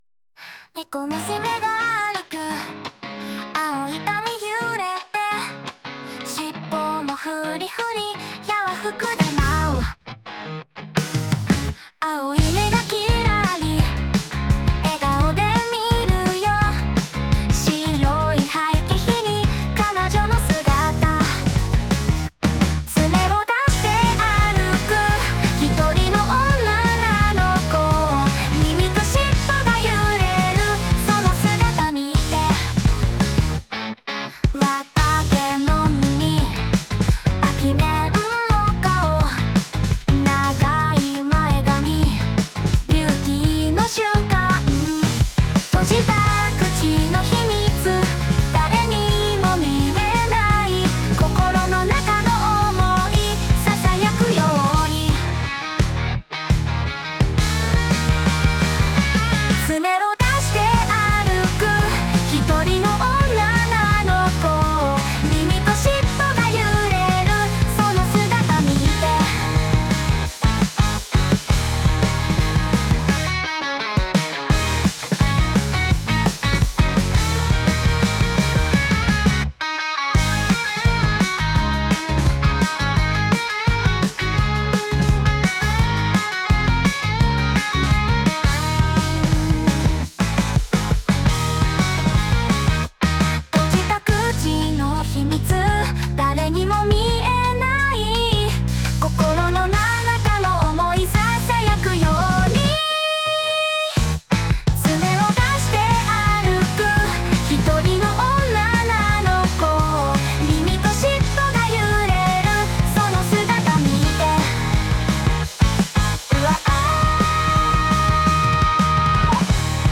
Music EDM Music